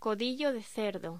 Locución: Codillo de cerdo
voz